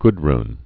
(gdrn) also Guth·run (gth-)